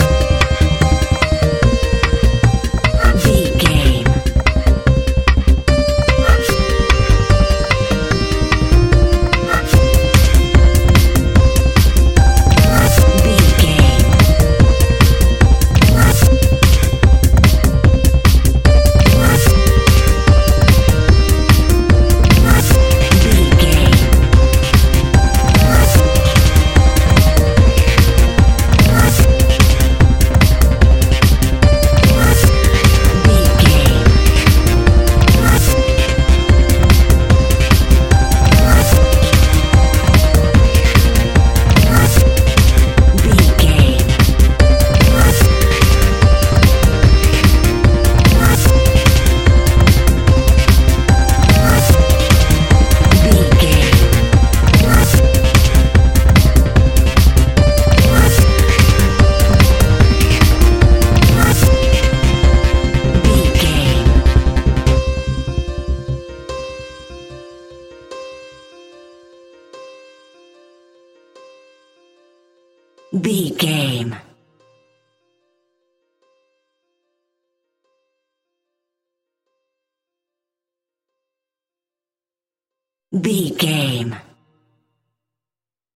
Epic / Action
Fast paced
Aeolian/Minor
groovy
uplifting
driving
energetic
repetitive
piano
drum machine
synthesiser
acid trance
uptempo
instrumentals
synth leads
synth bass